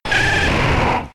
Cri de Florizarre K.O. dans Pokémon X et Y.